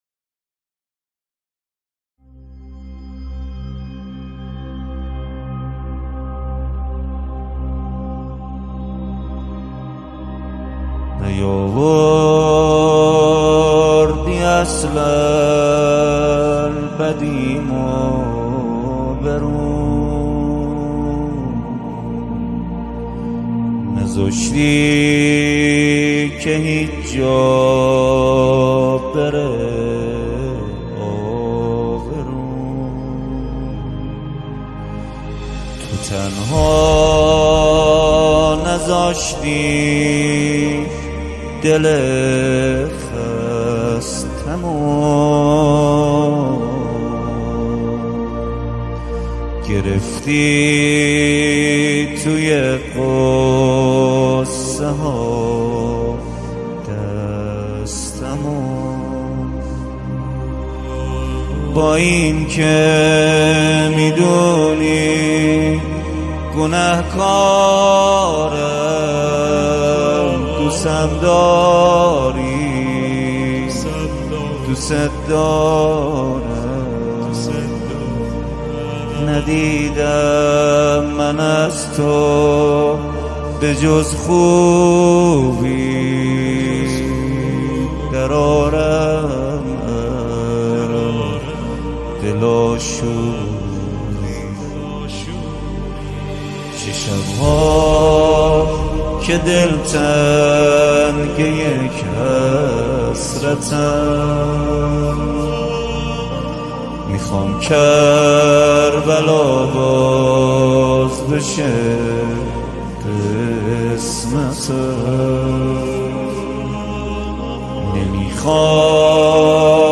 نماهنگ مناجاتی